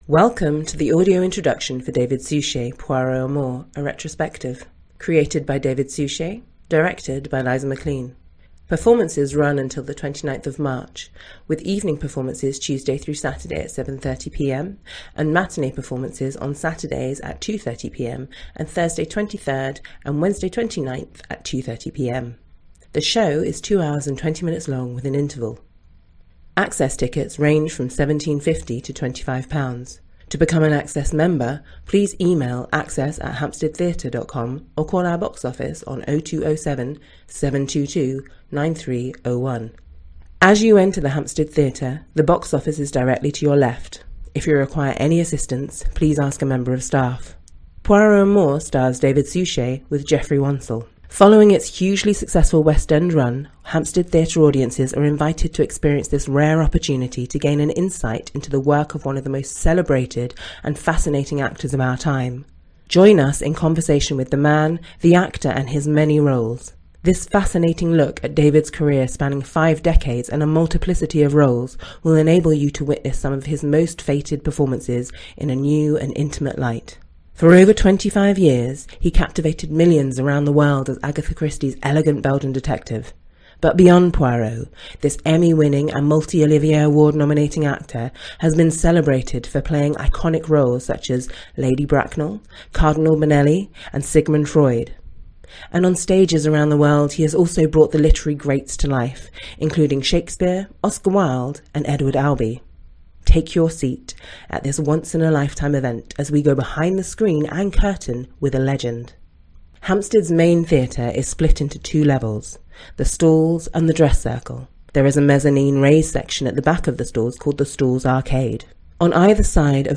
This is a pre-recorded introduction describing the set, characters and costumes and includes an interview with the cast.
David-Suchet-audio-intro-2.mp3